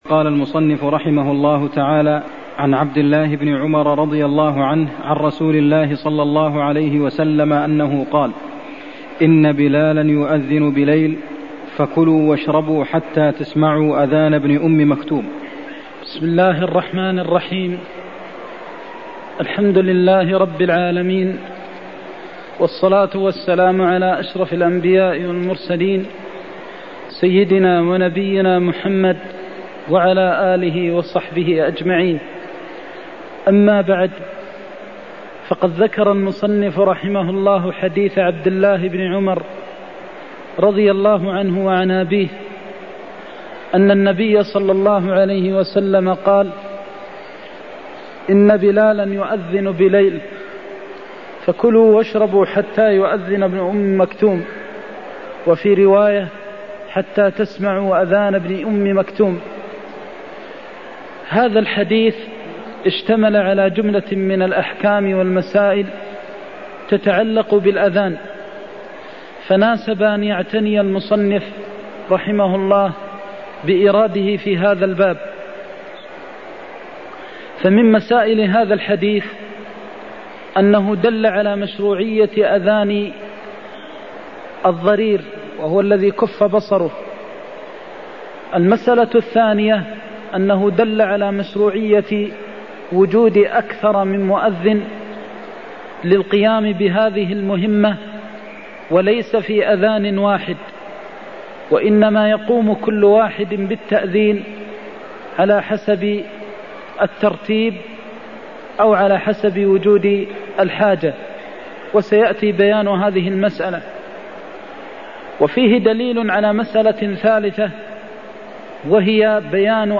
المكان: المسجد النبوي الشيخ: فضيلة الشيخ د. محمد بن محمد المختار فضيلة الشيخ د. محمد بن محمد المختار إن بلالا يؤذن بليل (62)